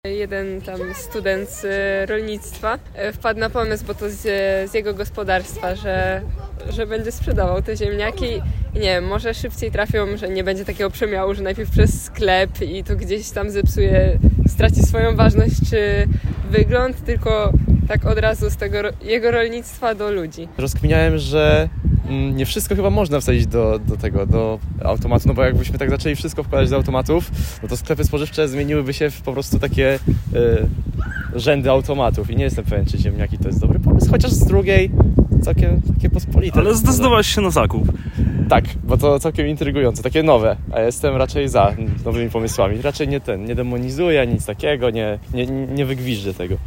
Oryginalny pomysł spodobał się pierwszym klientom, którzy zdecydowali się na zakup ziemniaków. Posłuchaj co mówią o nowym automacie: